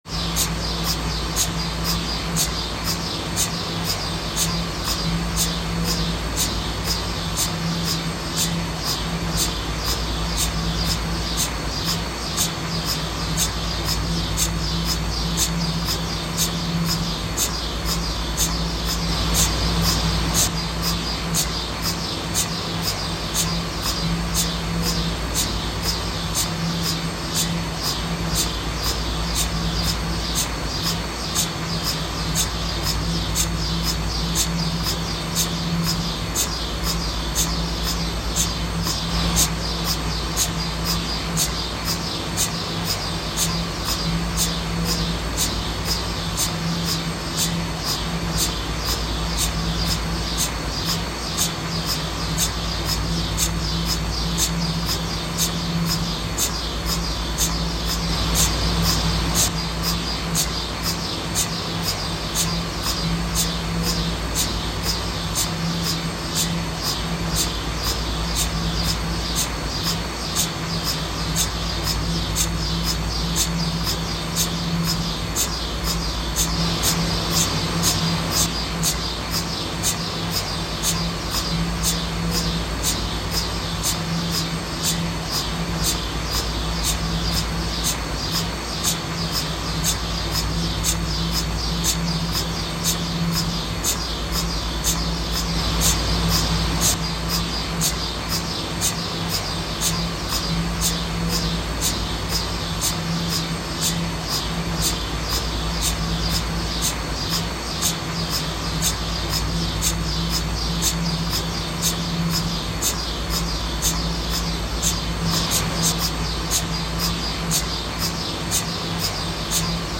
半導体量子コンピュータ冷凍機の音ダウンロード | blueqat
半導体量子コンピュータの音をダウンロードできます。 50Hzの関東バージョンです。